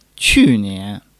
qu4-nian2.mp3